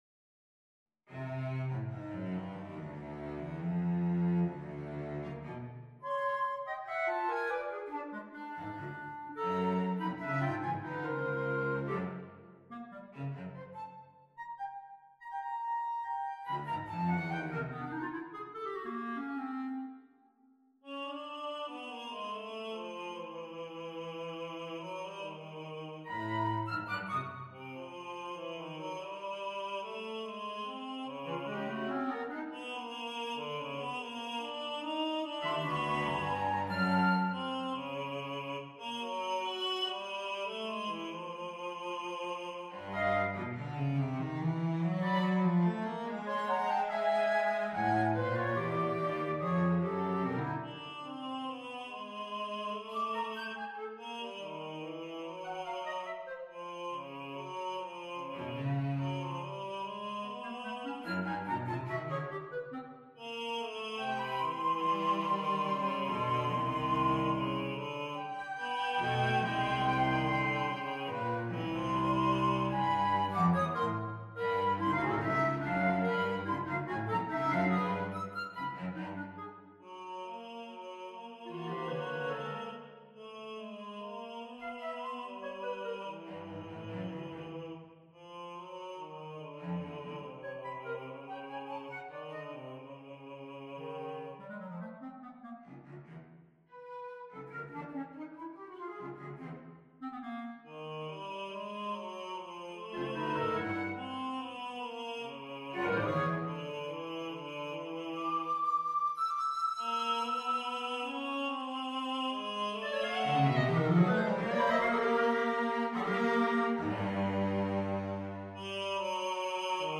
on a purpose-selected tone row
C-Db-Bb-Gb-G-A-Ab-E-F-Eb-D-B